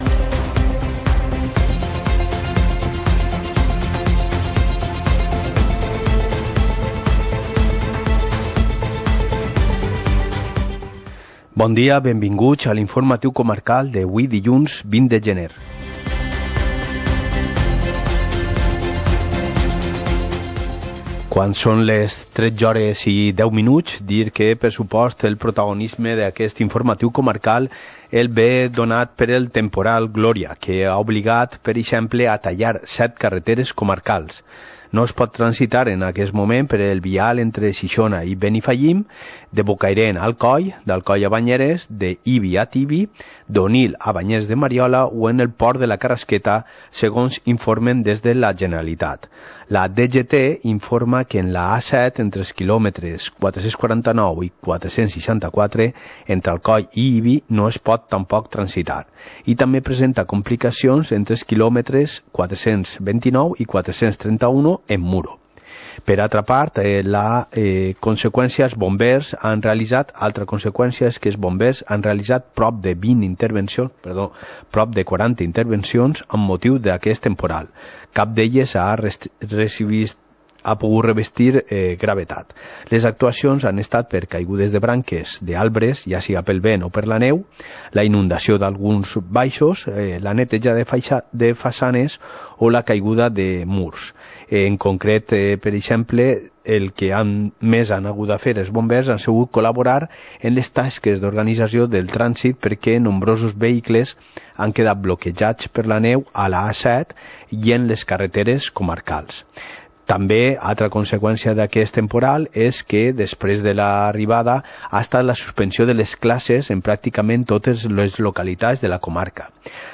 Informativo comarcal - lunes, 20 de enero de 2020